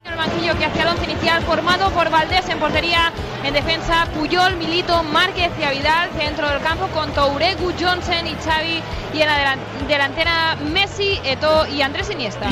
Micròfon sense fils al Camp Nou per donar l'aliniació de l'equip masculí del Futbol Club Barcelona
Esportiu